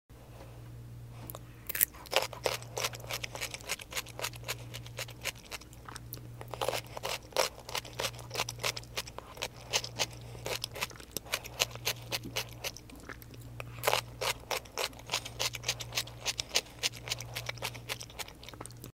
PREVIEW 2X SPEED ~ GARLIC DILL PICKLES ~ ASMR EATING SOUNDS NO TALKING